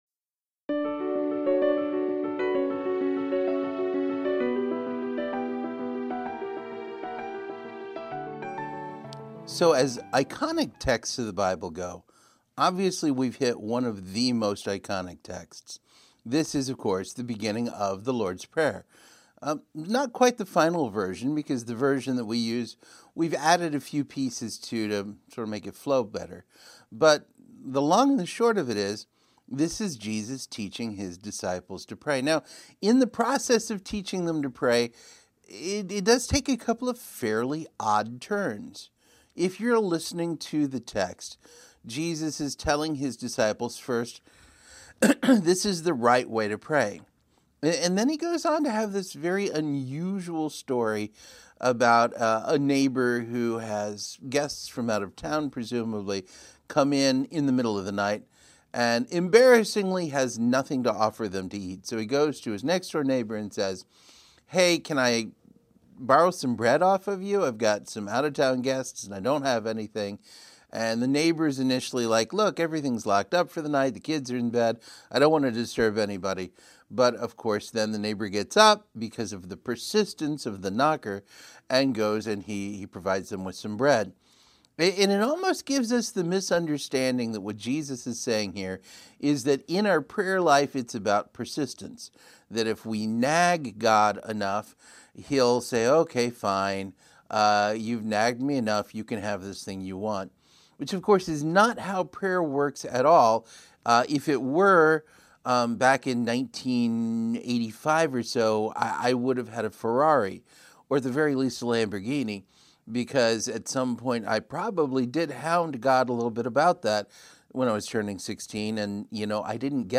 July-27-Sermon-Praying-Well.mp3